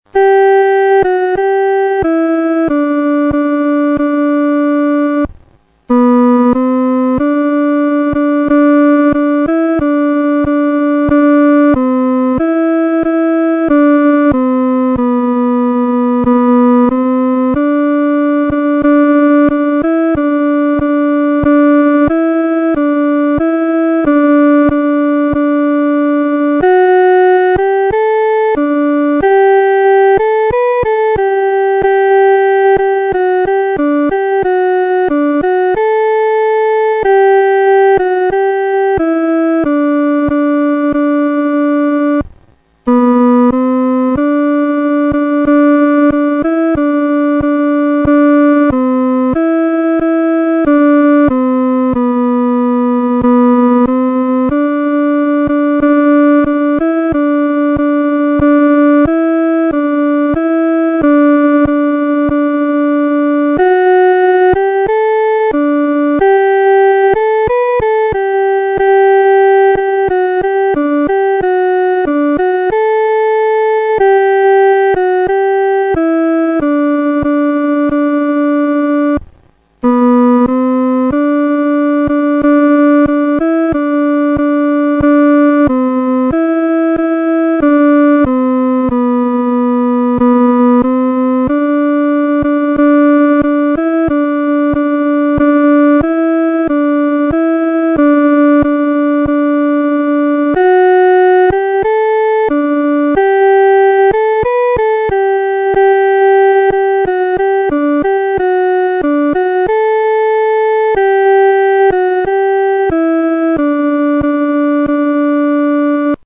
独奏（第二声）